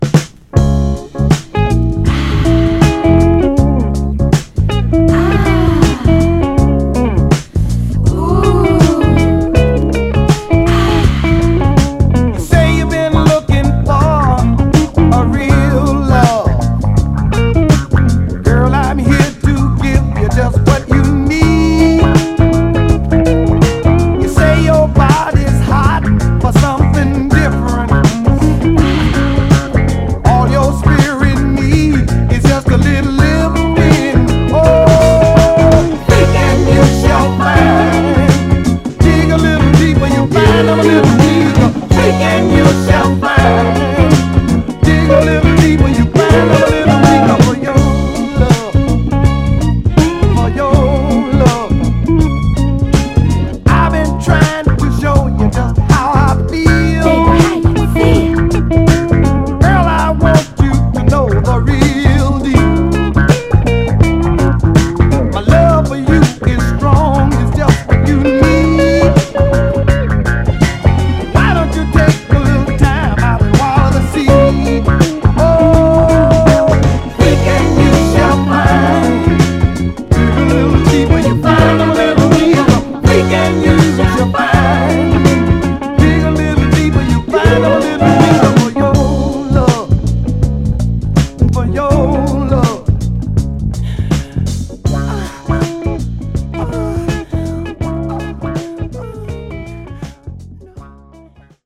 南部的に太いボトムとギターのトラックにほっこりしたヴォーカルが絡む、ファンキー・ソウルです。